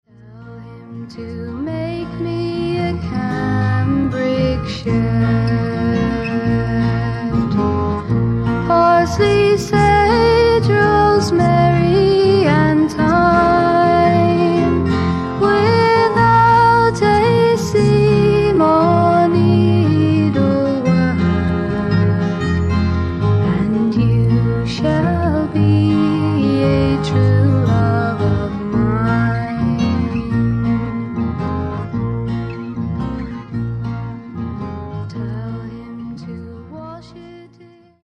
FEMALE FOLK / PSYCHEDEIC POP